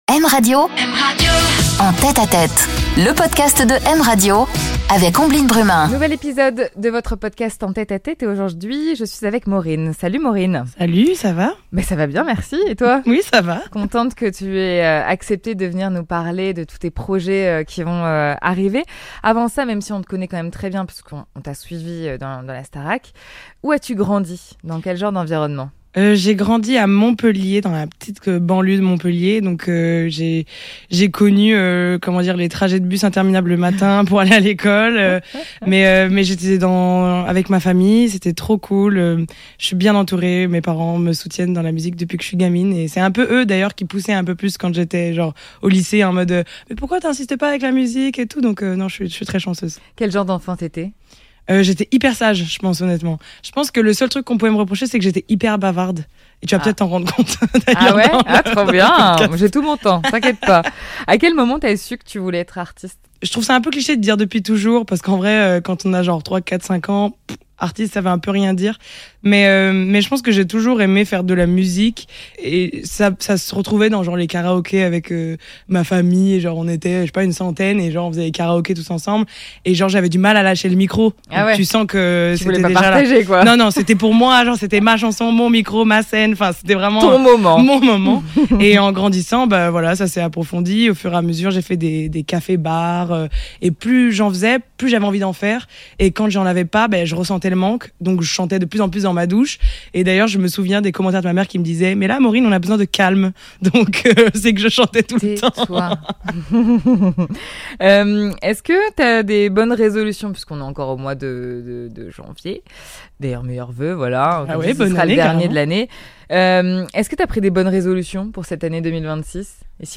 Elle nous interprète aussi son titre en live !